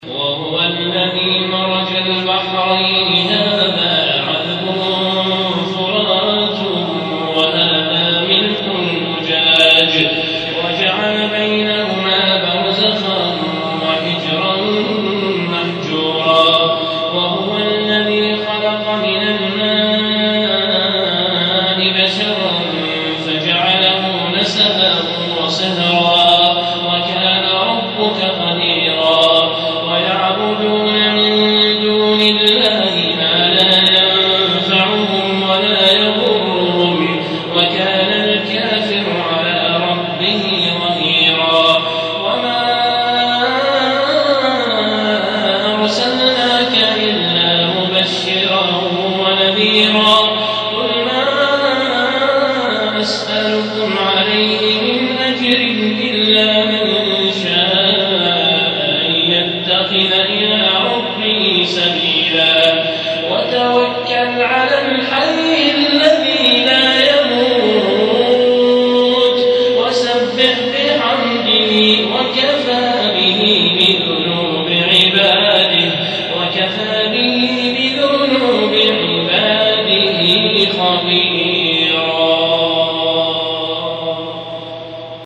هذا تسجيل من صلاة التراويح وصوته يهبل